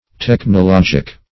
technologic - definition of technologic - synonyms, pronunciation, spelling from Free Dictionary
Technologic \Tech`no*log"ic\, a.